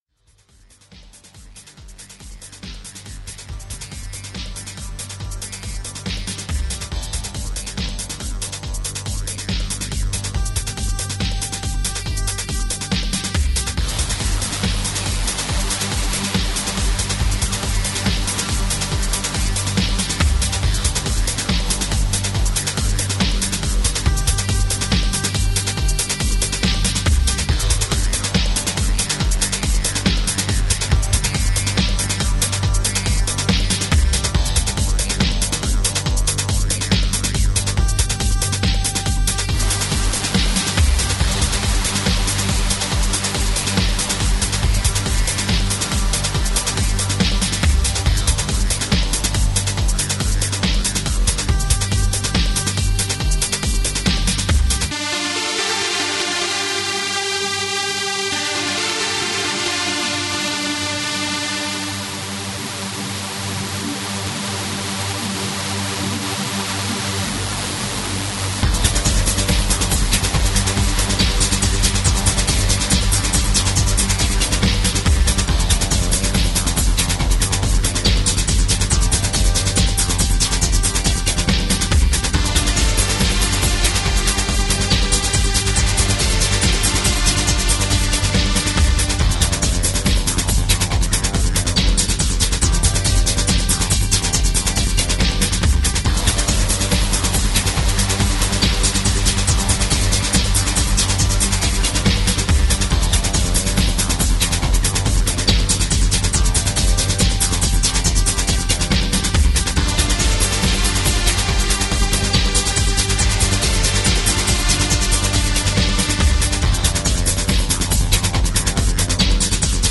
Upbeat underground with shades of new wave.
Tagged as: Electronica, Techno, IDM